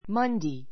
Monday 小 A1 mʌ́ndei マ ンデイ 名詞 複 Mondays mʌ́ndeiz マ ンデイ ズ 月曜日 ⦣ 週の第2日.